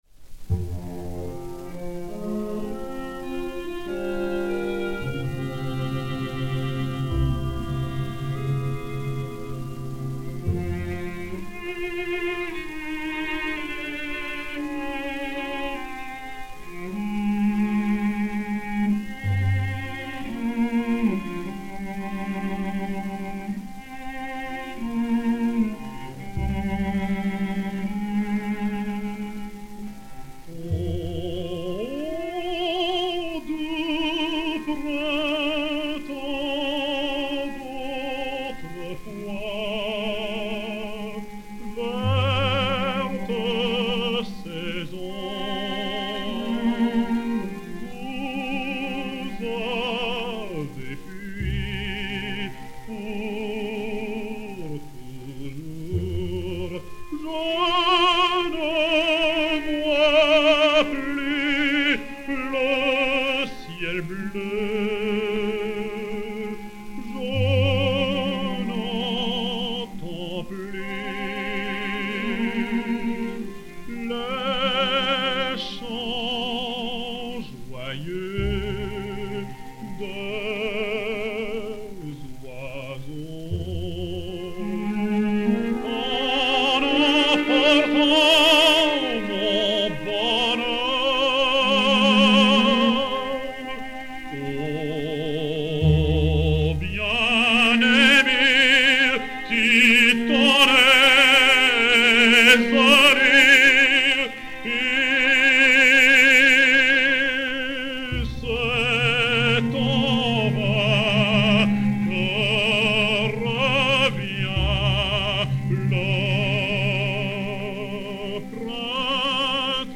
ténor
violoncelle